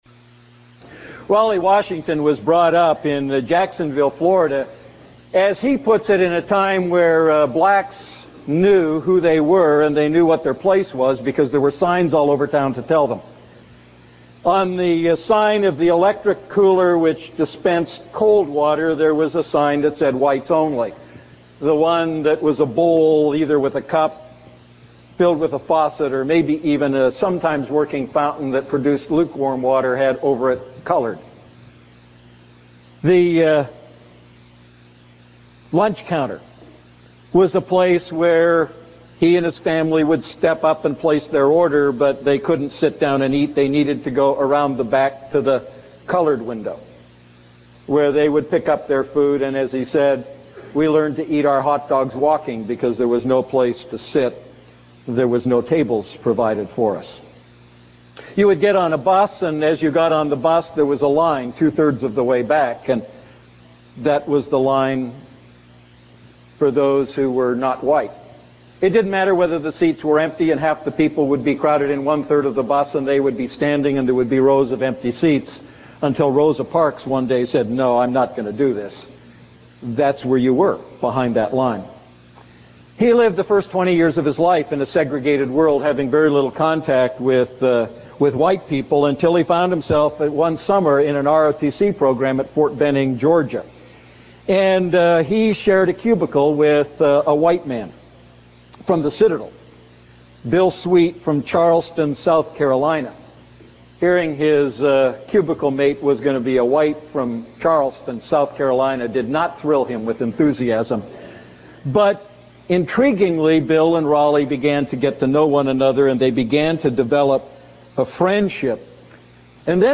A message from the series "Ephesians Series II."